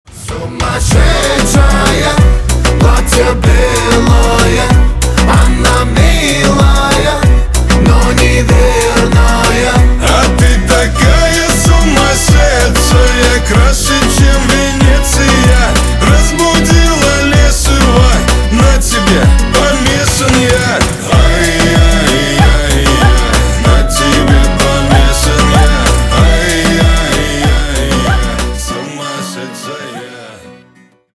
на русском на девушку веселые